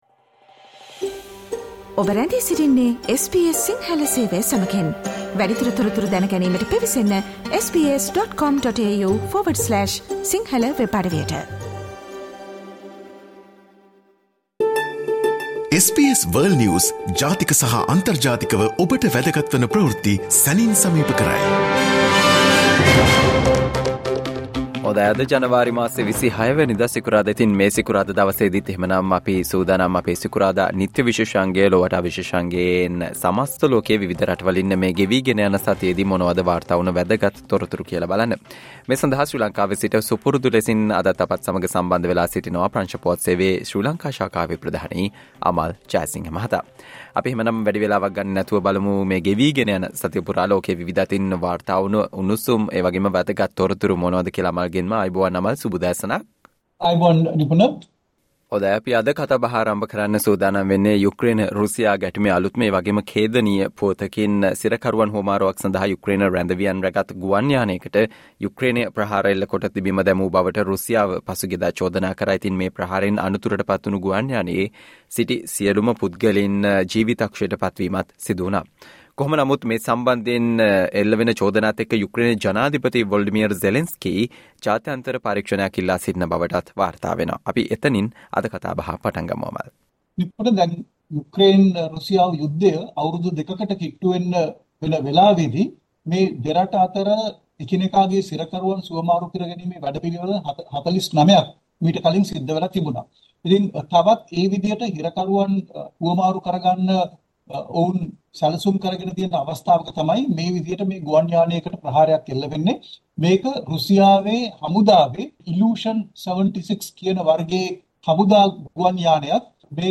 listen to the world’s most prominent news highlights.